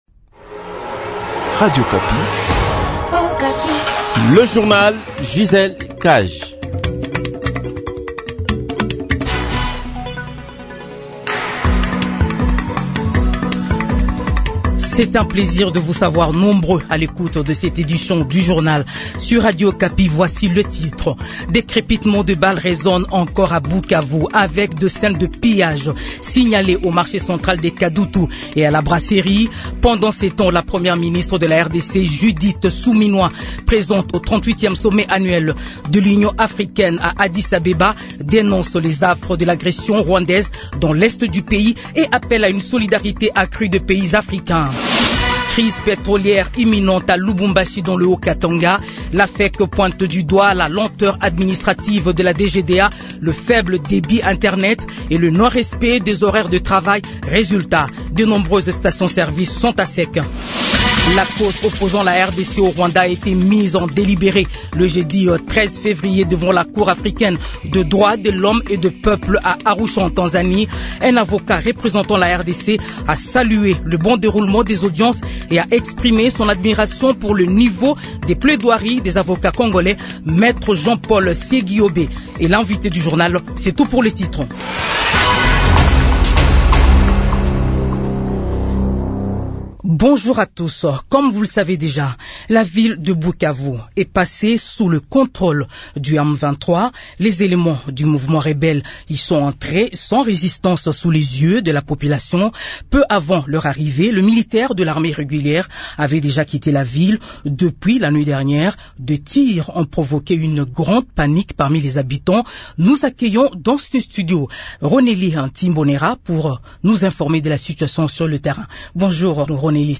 Journal 15 heures
2/Sud-Kivu : Témoignage d’un habitant décrit la situation militaire à Bukavu